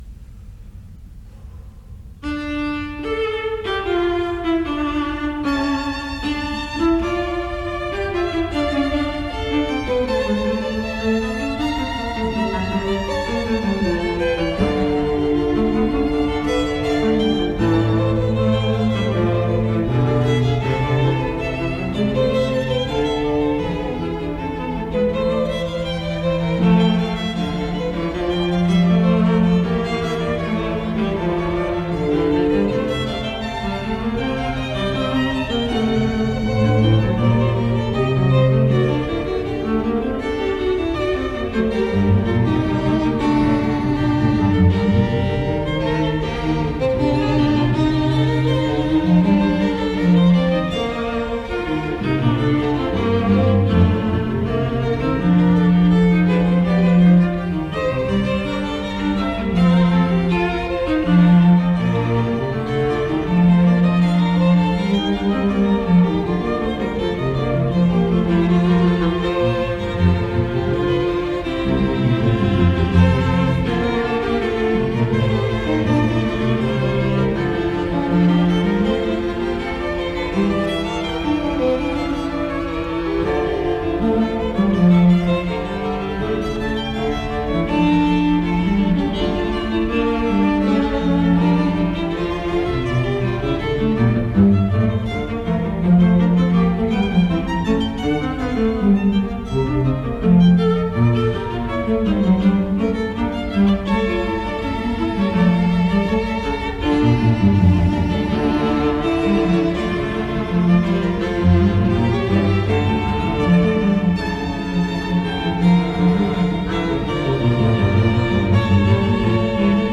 La Fuga es un procedimiento de construcción musical o forma musical que se podría definir como una composición polifónica basada en el contrapunto entre varias voces.